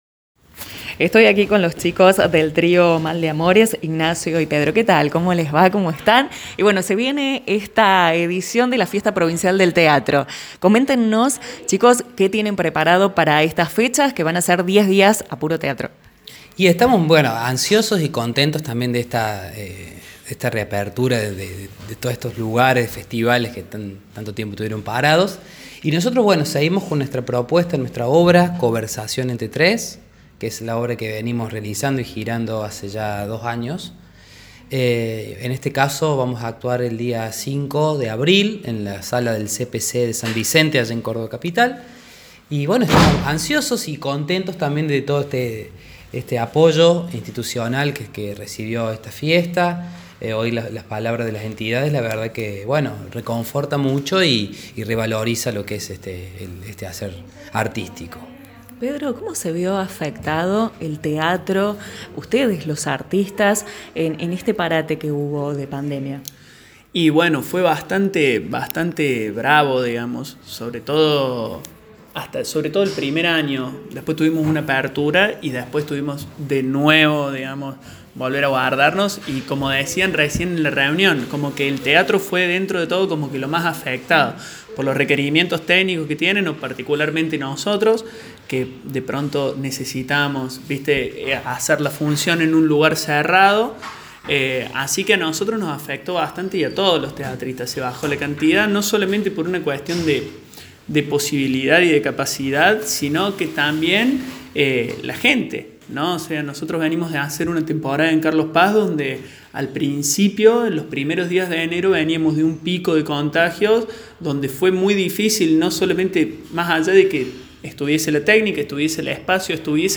AUDIO l El Trío teatral «Mal de amores» dialogó con «La Mañana Informal»